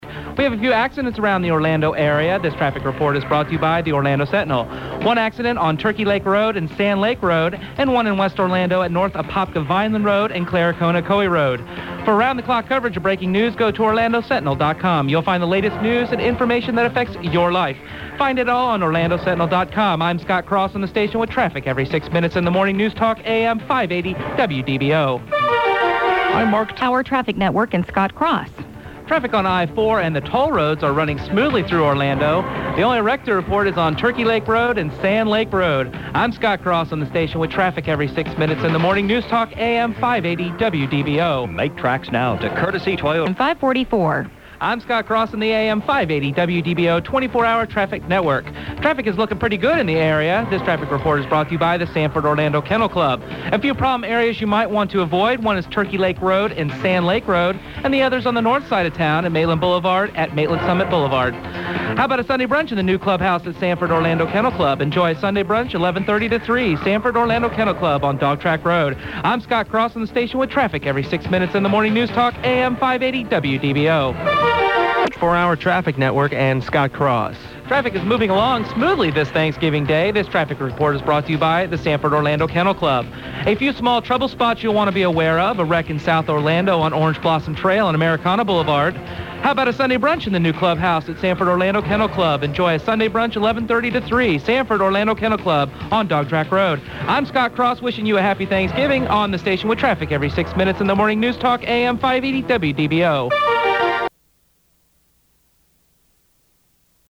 Partner Demos